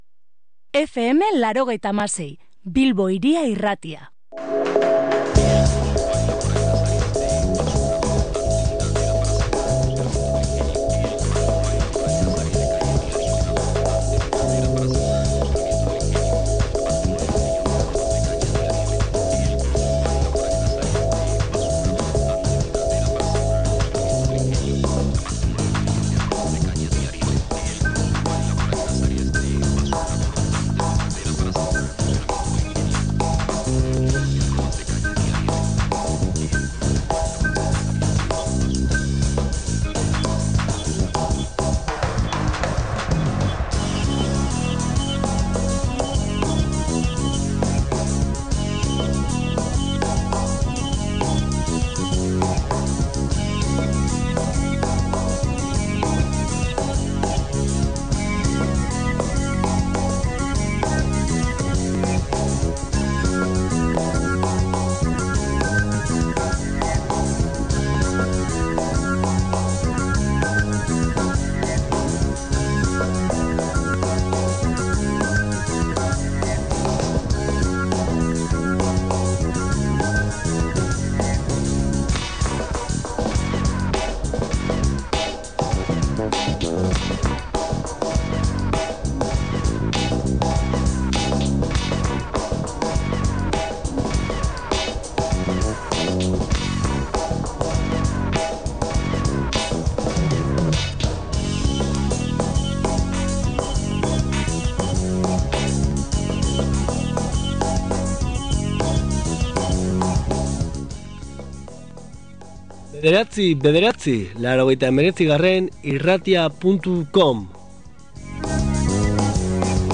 Musika hartu dugu hizpide, eta musika entzungai.